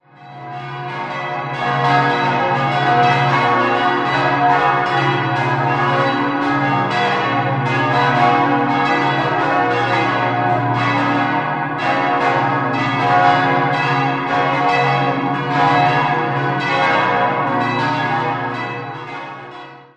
Die geräumige Konradskirche wurde in den Jahren 1950 bis 1952 als einfache Hallenkirche errichtet. 6-stimmiges ausgefülltes und erweitertes B-Dur-Geläute: b°-c'-d'-f'-g'-b' Die Glocken wurden in den Jahren 1950/51 und 1961 von Johann Hahn in Landshut gegossen.